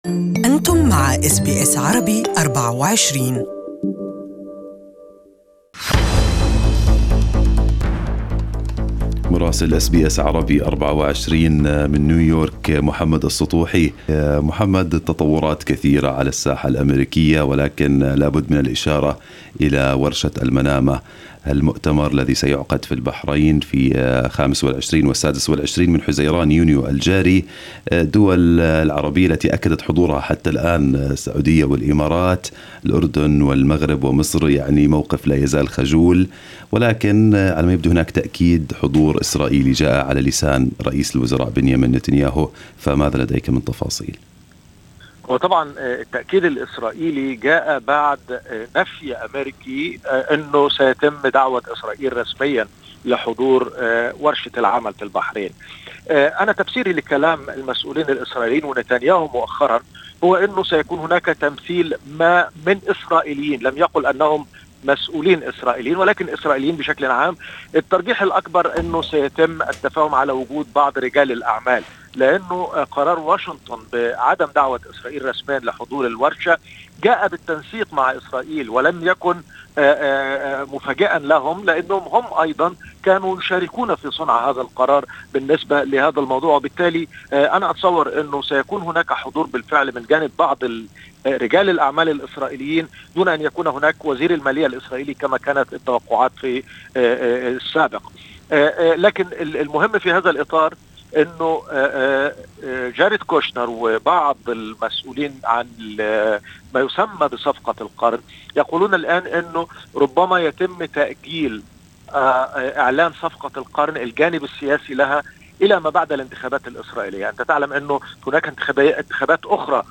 Listen to the full report in Arabic